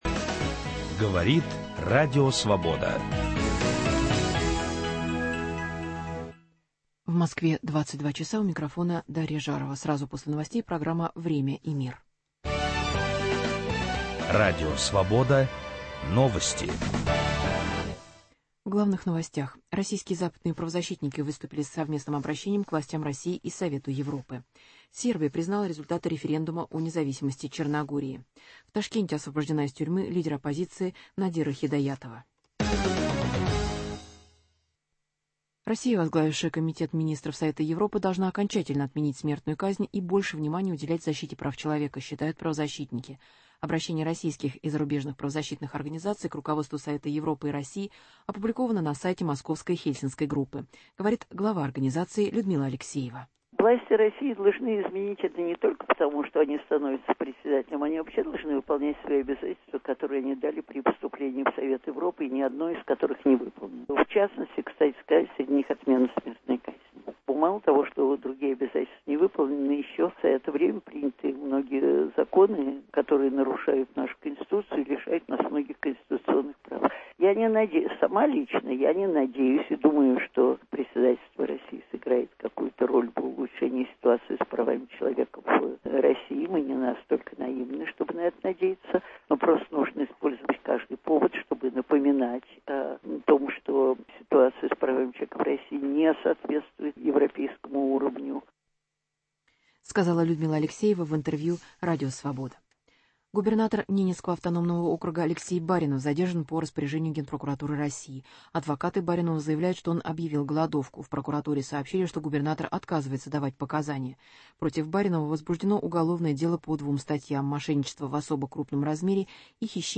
Интервью с профессором Ричардом Пайпсом о состоянии американо-российских отношений.